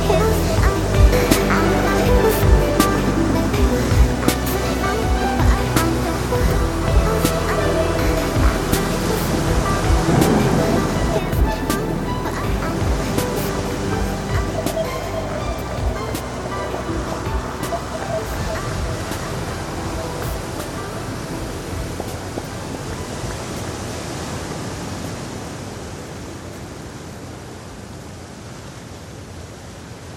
Musique Electronique